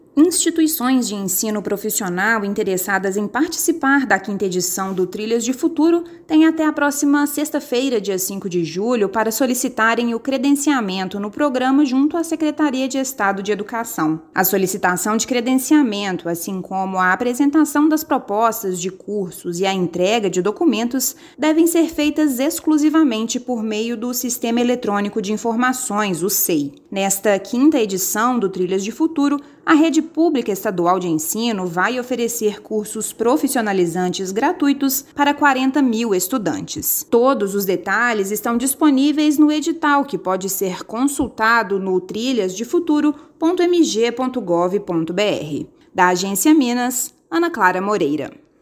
Serão disponibilizadas 40 mil vagas para estudantes da rede pública estadual de ensino; saiba como participar. Ouça matéria de rádio.